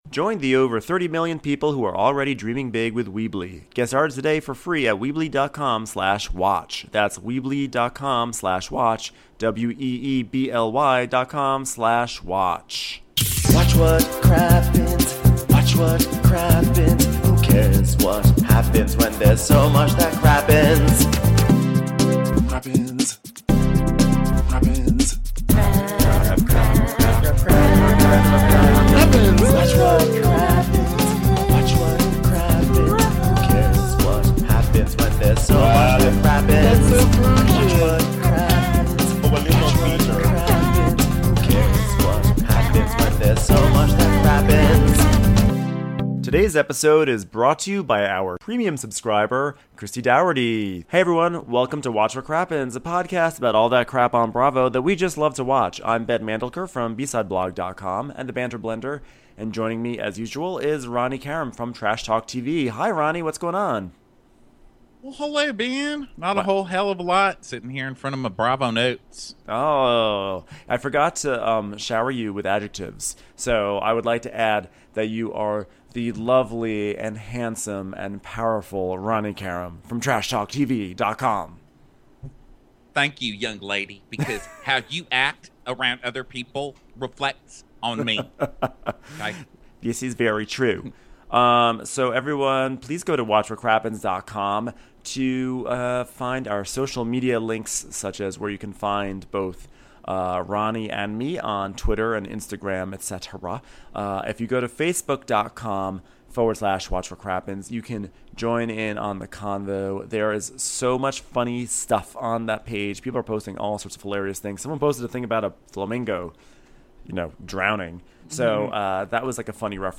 Prepare for Patti LuPone impersonations